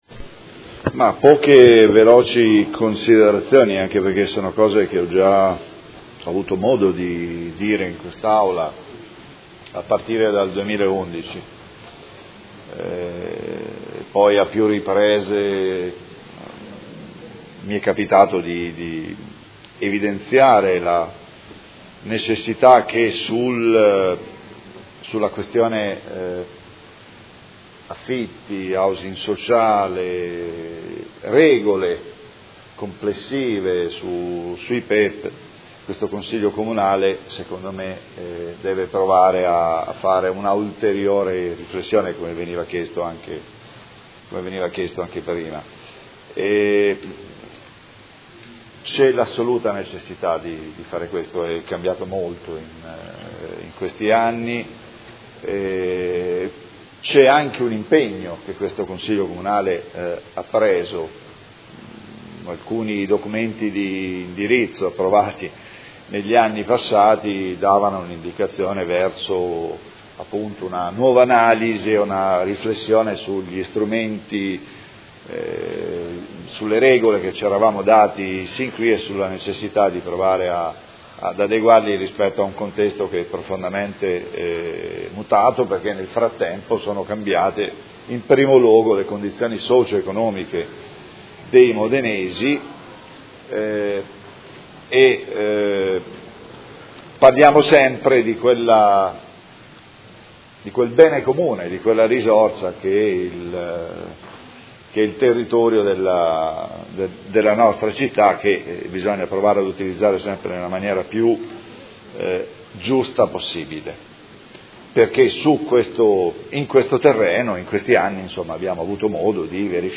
Seduta del 20/07/2017 Dibattito. Alloggi con vincolo di locazione a termine nel P.E.E.P. “Panni”, lotto 7 – Scadenza del vincolo di destinazione alla locazione - Apposizione di nuovo vincolo decennale in sostituzione o trasferimento dell’obbligo di locazione a termine da un ugual numero di alloggi nel Comparto “Ghiaroni” - Accoglimento e condizioni